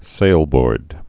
(sālbôrd)